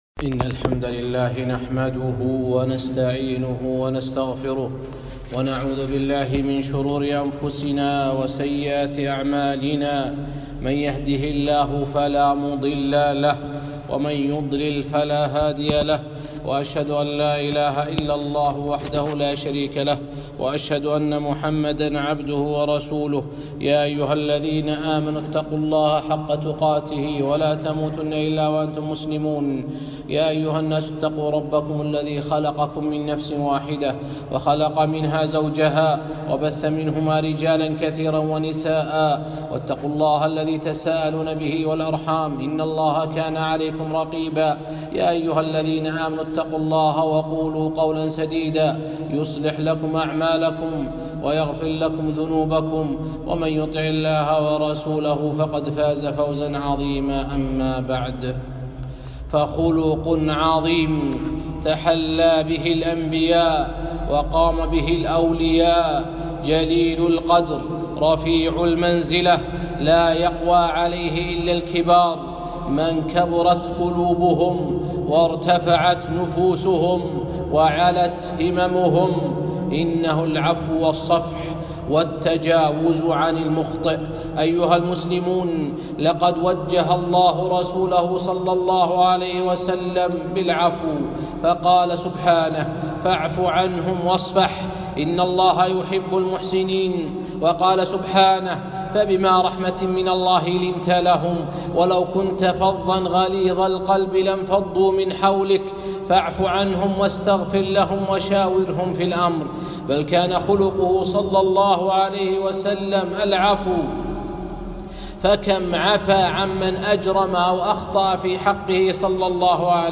خطبة - العفو 3-5-1442